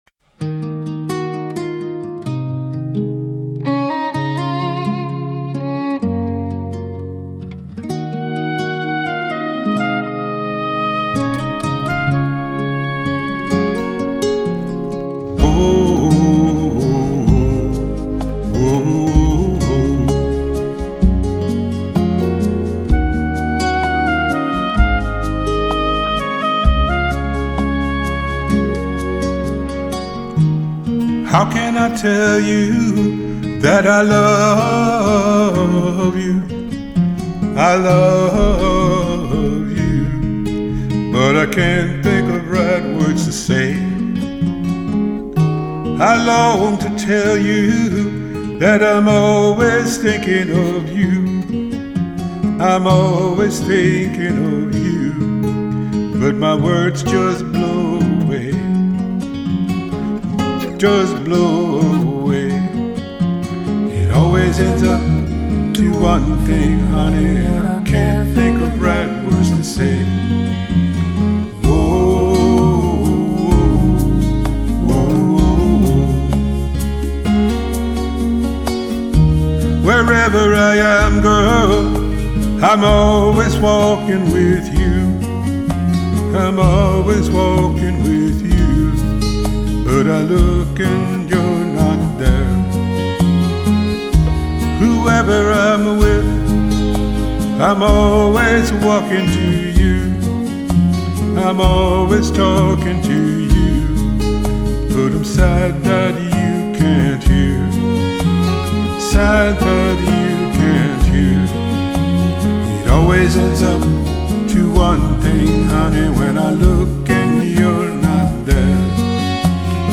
with me playing and singing
second guitar part